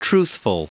Prononciation du mot truthful en anglais (fichier audio)
Prononciation du mot : truthful